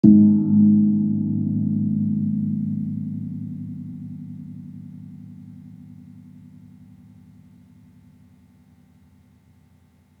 Gong-G#2-f.wav